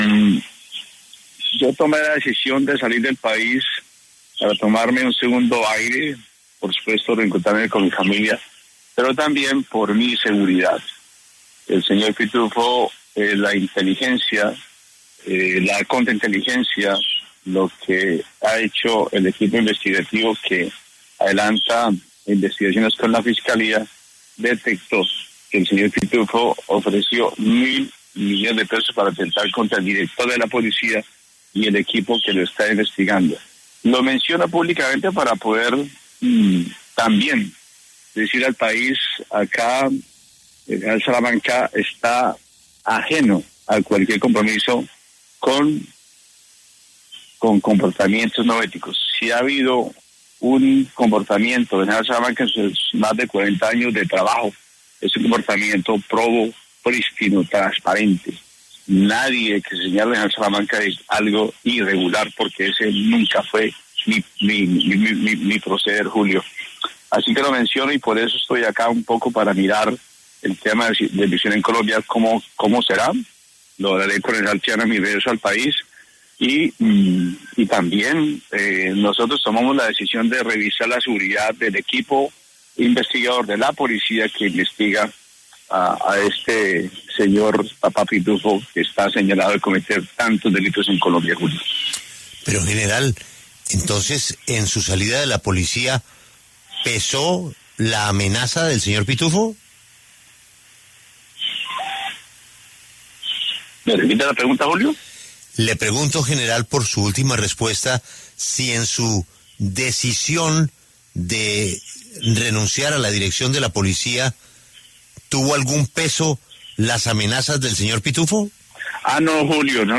El general (r) William Salamanca, exdirector de la Policía Nacional, pasó por los micrófonos de La W, con Julio Sánchez Cristo, y allí se refirió al caso de ‘Papá Pitufo’, en donde reveló que este ofreció dinero para atentar contra su vida cuando estaba al frente de la institución.